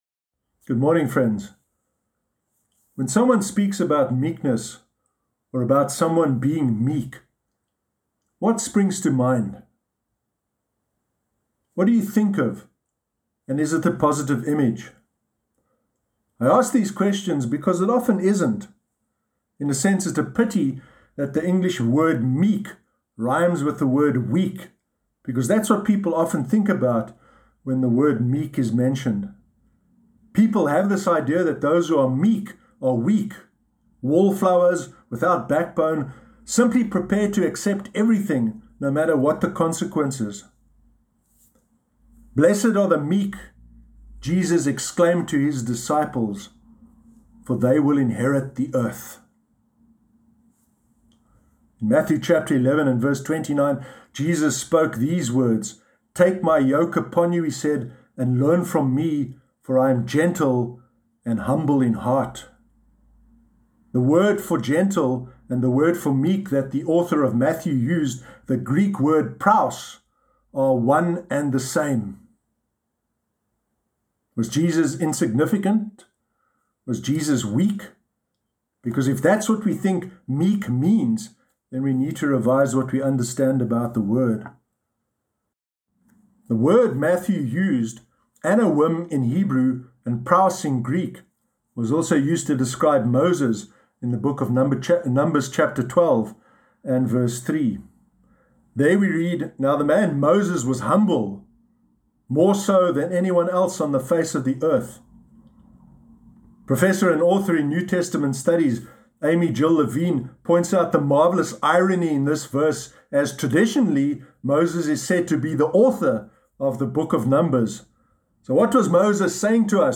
Sermon Sunday 9 May 2021
sermon-sunday-9-may-2021.mp3